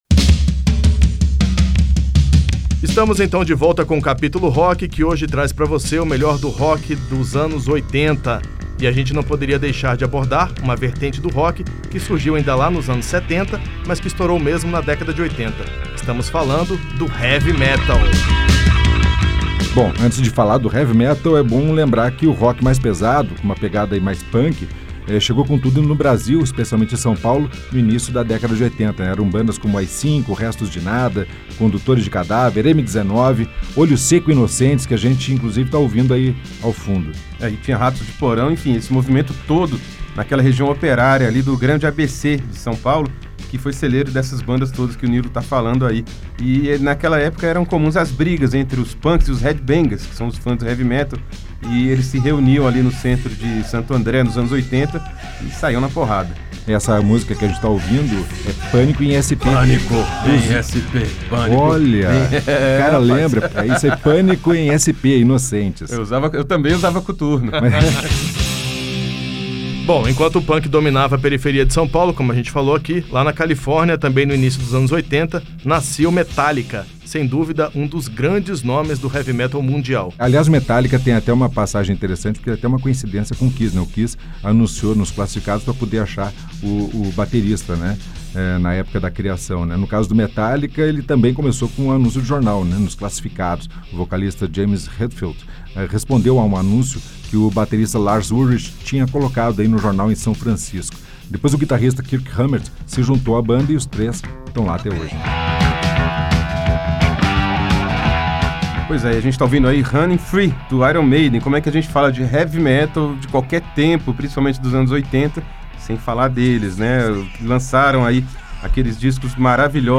Rock dos Anos 80